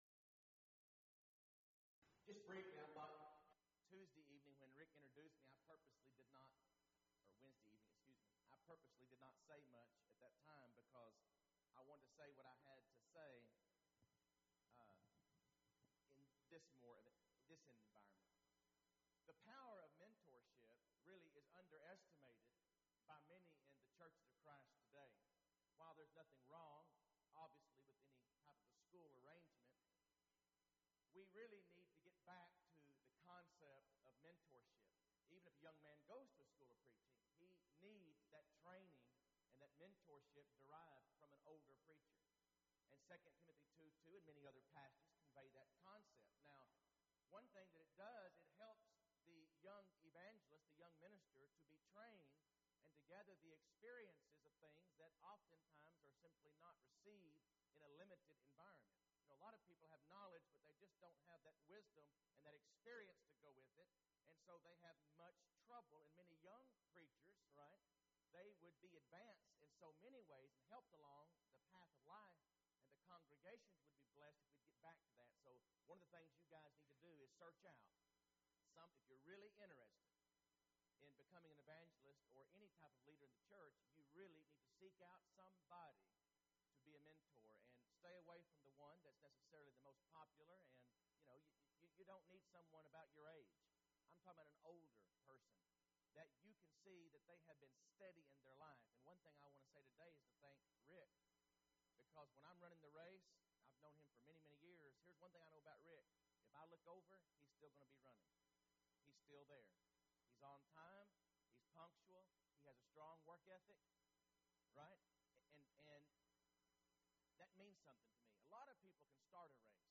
Event: 4th Annual Men's Development Conference
If you would like to order audio or video copies of this lecture, please contact our office and reference asset: 2020MDC23